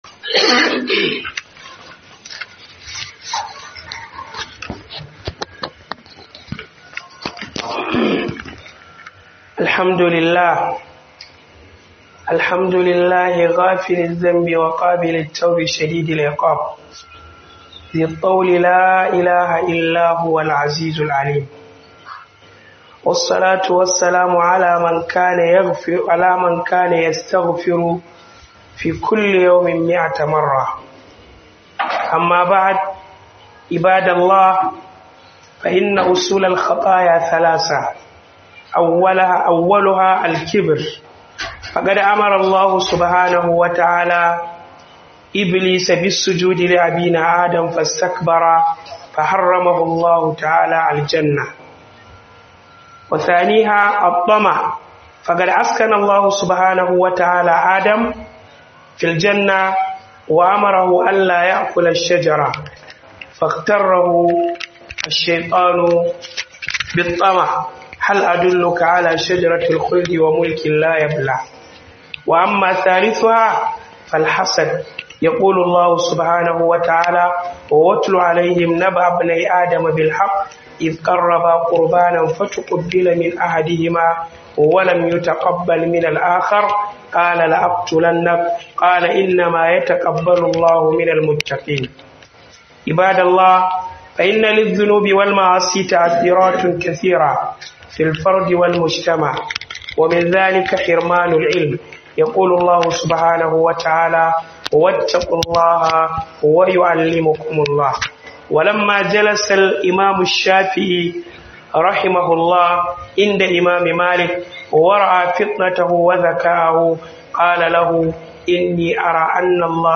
Abubuwan Da Zunubai Suke Kawowa Daga Misau - HUDUBA